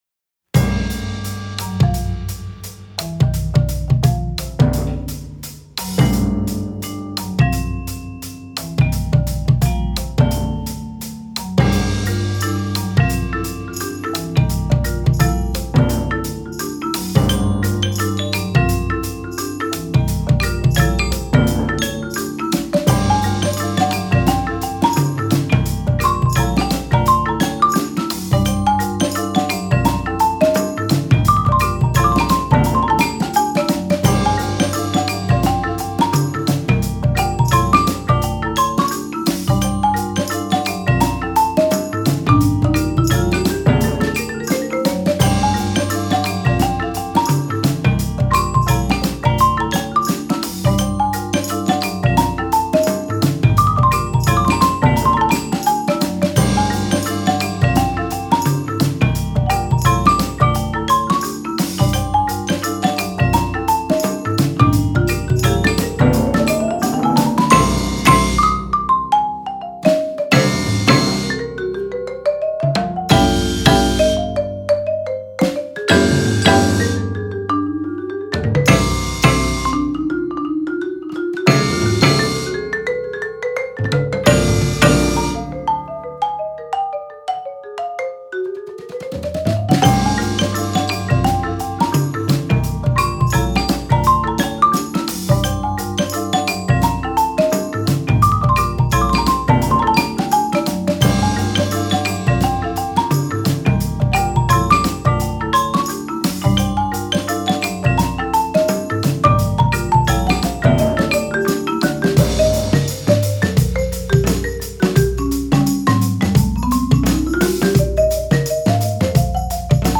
Players: 13